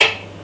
bouncehard2.wav